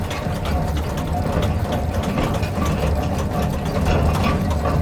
artillery-rotation-loop-1.ogg